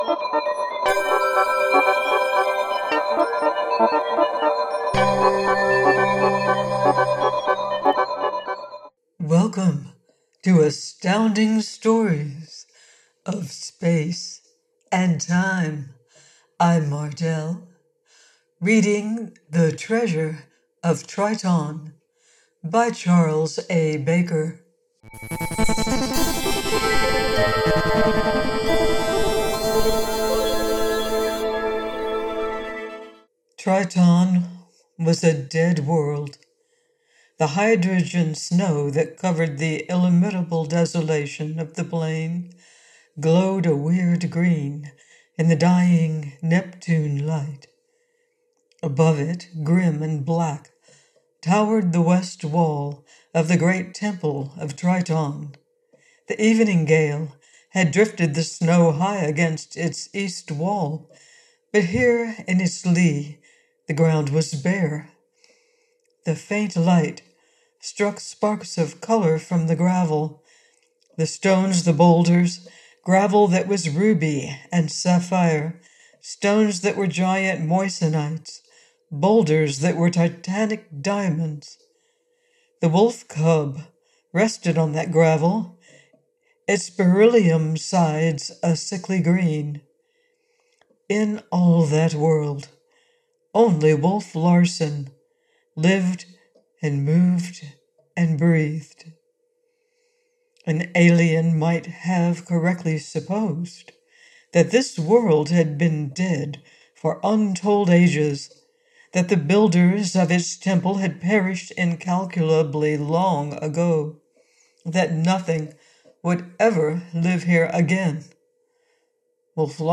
Astounding Stories of Space and Time - Audiobooks of Classic science fiction and fantasy from your favorite authors, H. Beam Piper, Phillip K. Dick, Henry Kuttner, Andre Norton, Poul Anderson, Robert Silverberg, Harry Harrison and many more.